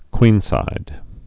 (kwēnsīd)